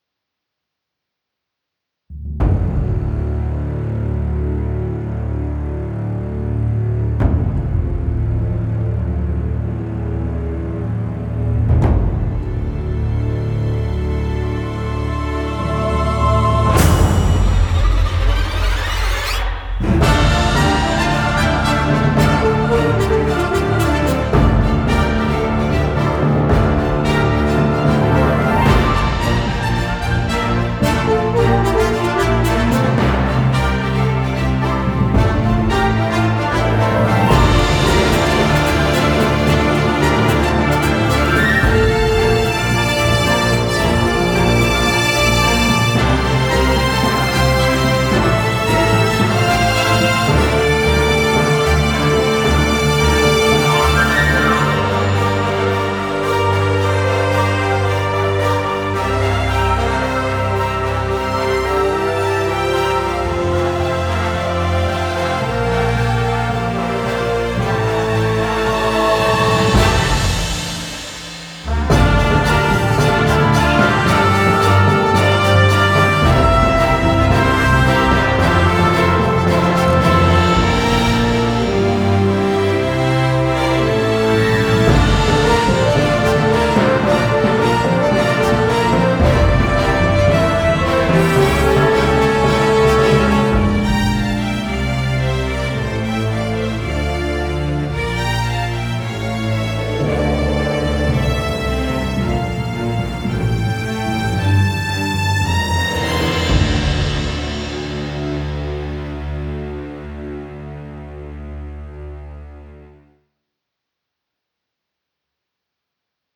「インスト部門」最優秀作品です。